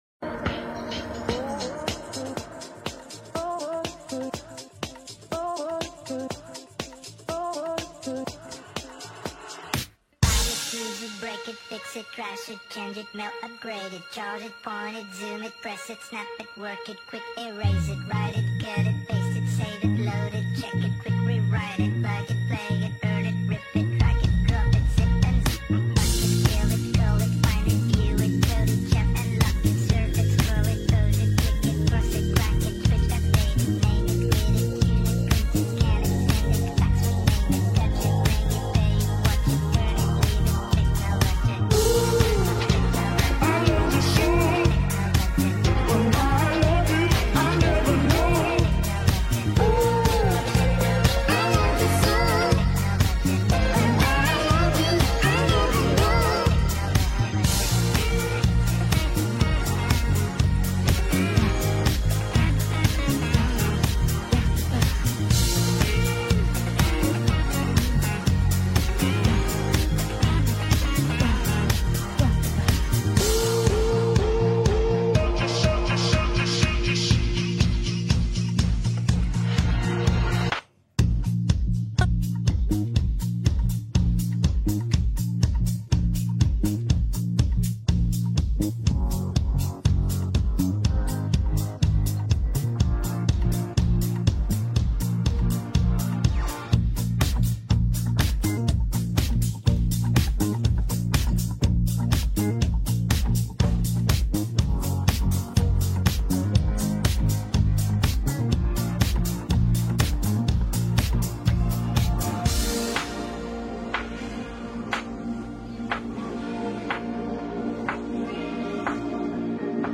has different layers and instruments